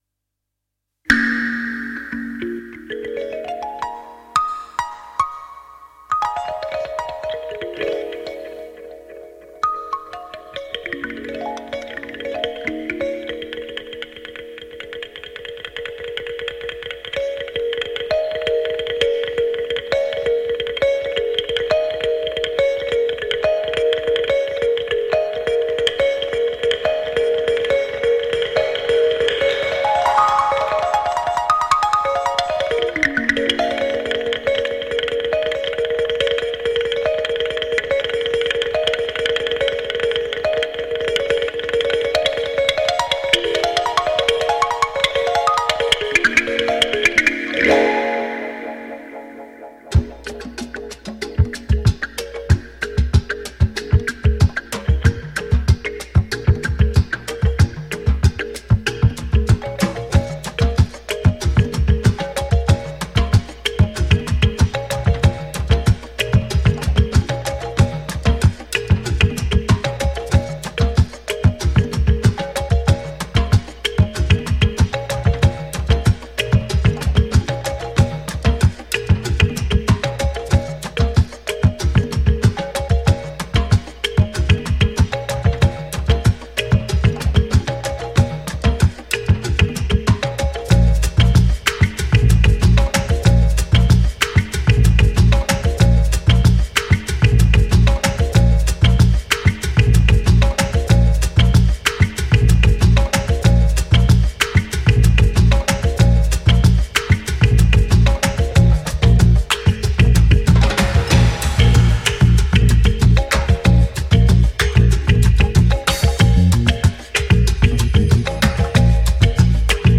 Afro, Balearic, Cosmic